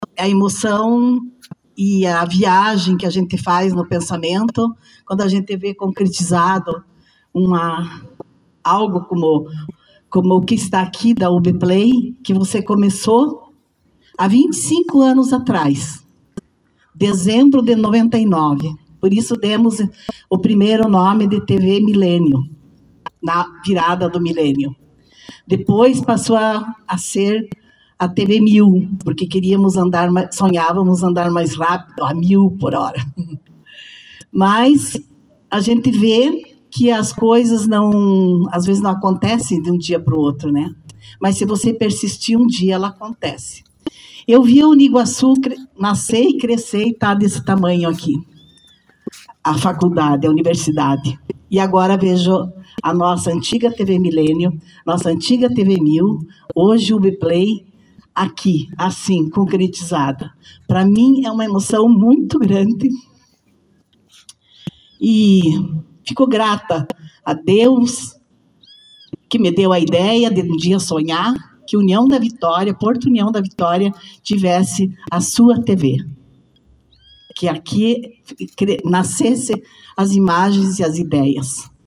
Na noite desta quinta-feira (14), a TV UBPLAY inaugurou oficialmente seu novo e moderno prédio em União da Vitória.